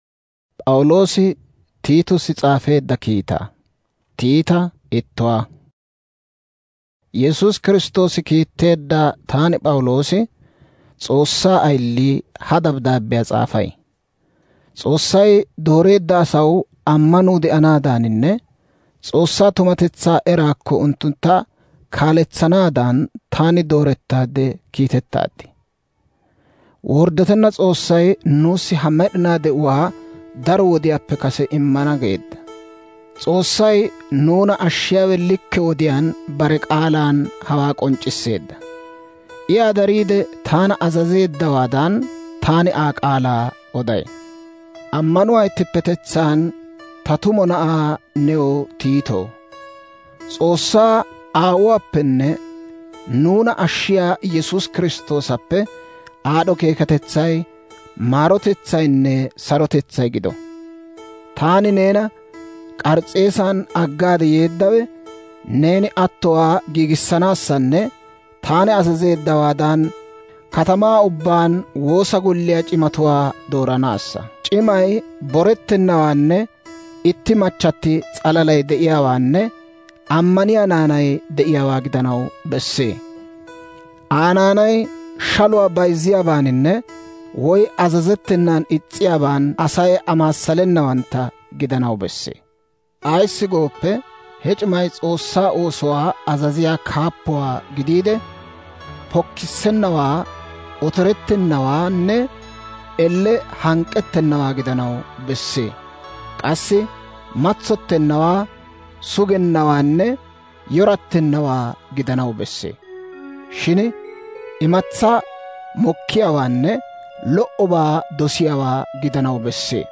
Audio Bible Download